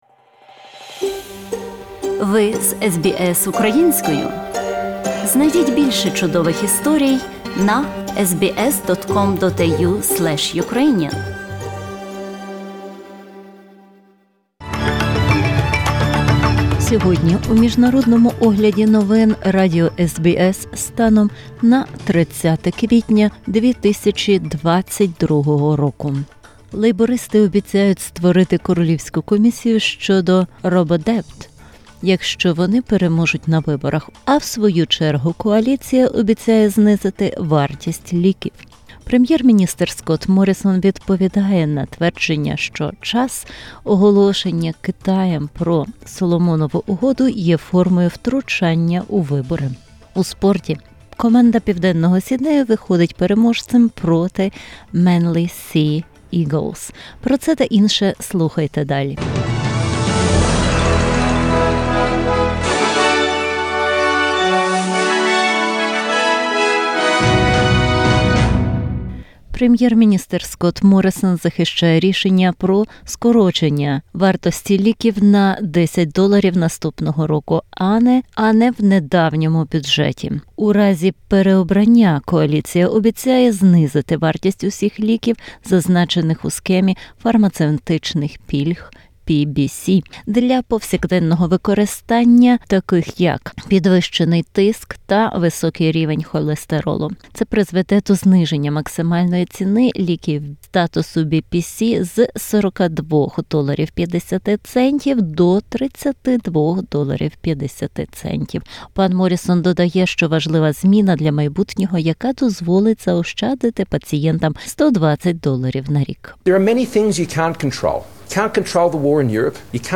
SBS news in Ukrainian - 30/04/2022